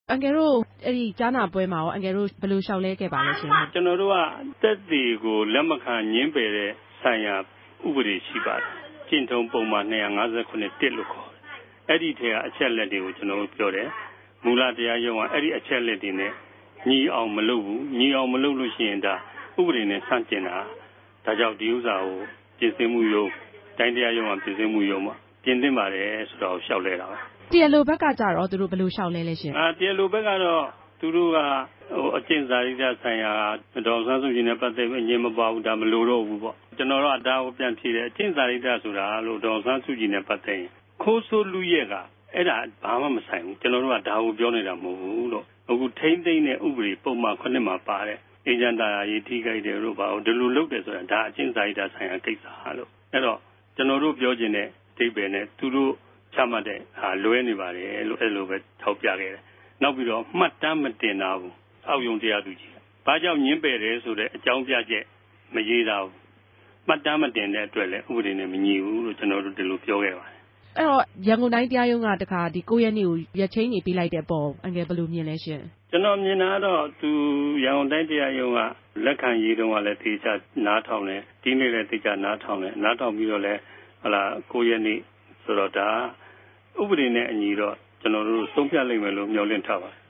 ဆက်သြယ်မေးူမန်းခဵက်။